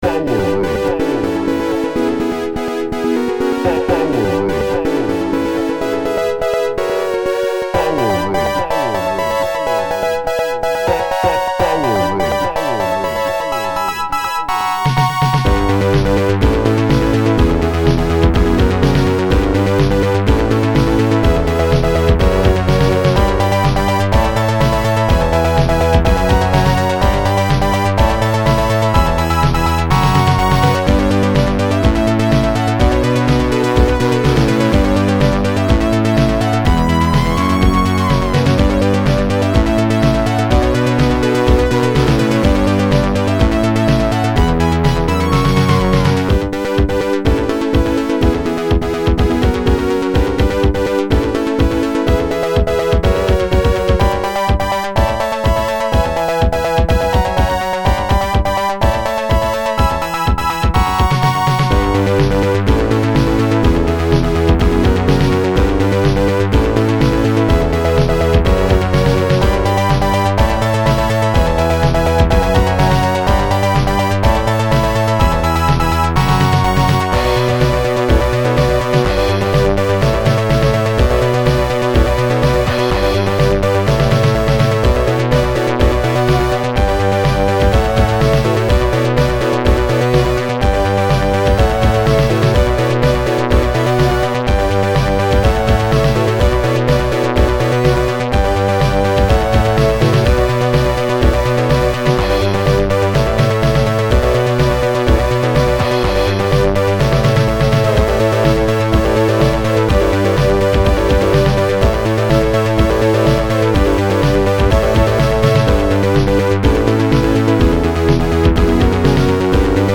Audio: MSX-Audio (mono)